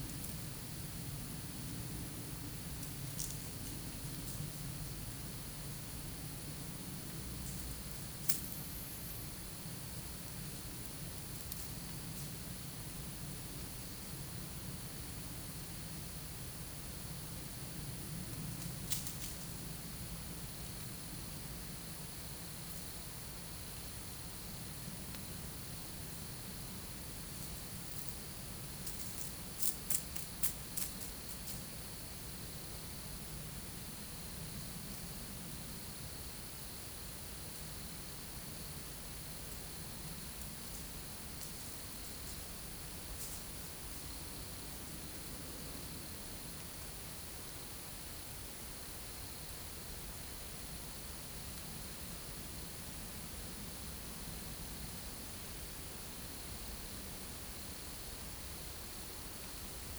PAM - Renecofor CATAENAT sunset+60
Nyctalus leisleri
Eptesicus serotinus
Plecotus auritus
Myotis nattereri
Myotis daubentonii